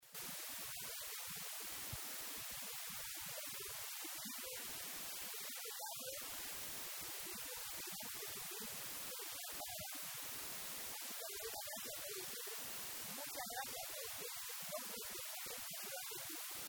En sus pocas palabras expresadas al auditorio, Alicia Alonso recordó que la vida es todo un reto que nadie debe privarse de vivir.